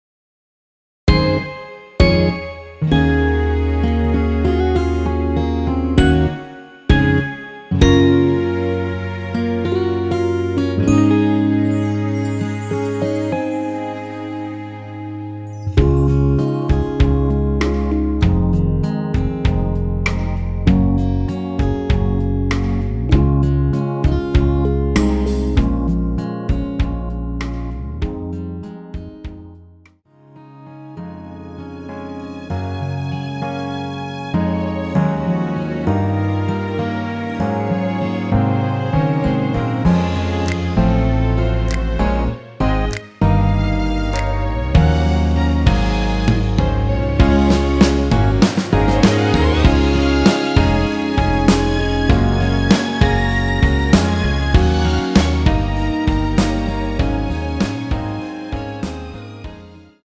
원키에서(-1)내린 MR입니다.
Db
앞부분30초, 뒷부분30초씩 편집해서 올려 드리고 있습니다.
중간에 음이 끈어지고 다시 나오는 이유는